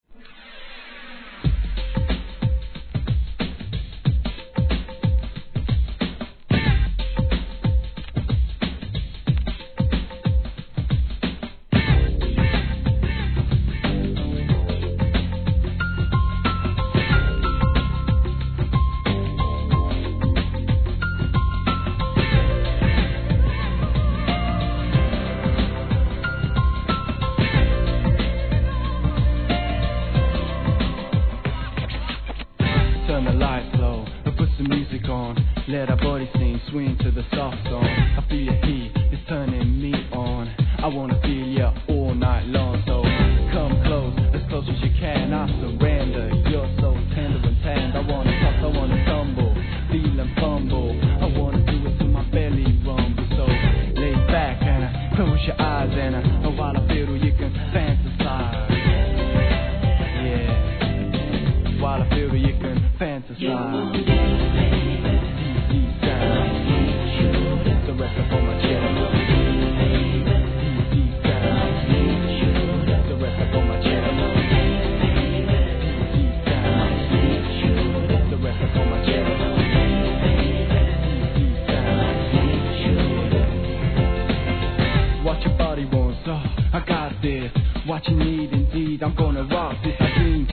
PIANOが優しく乗る清涼感あるトラックにコーラスもスピリチュアルな雰囲気を漂わす1992年UK HIP HOP!!